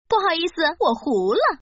Index of /client/common_mahjong_tianjin/mahjongjinghai/update/1130/res/sfx/woman/